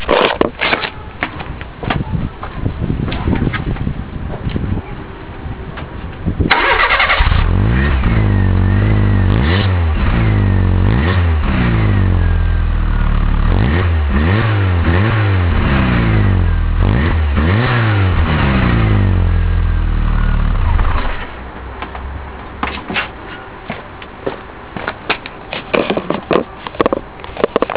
Nagrałem sobie mój wydech:
Mój WM Sport z silencerem: